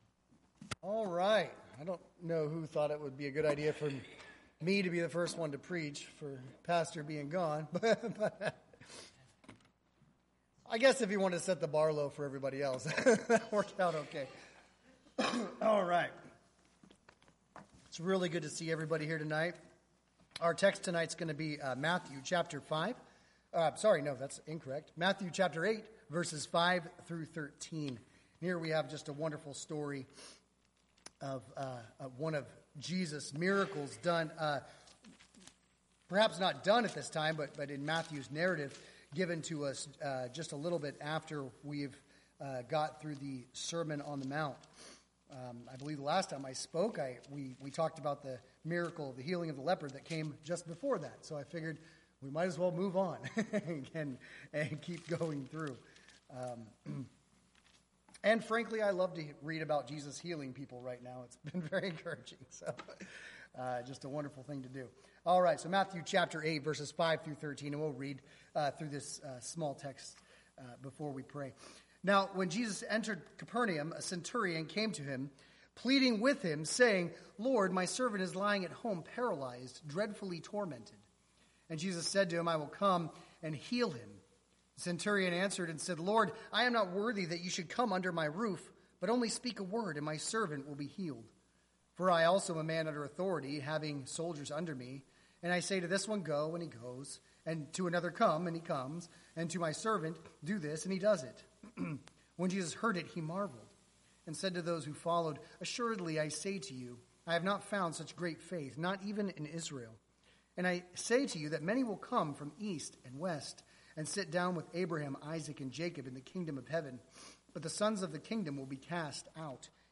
Matthew Passage: Matthew 8:5-13 Service Type: Wednesday Evening Topics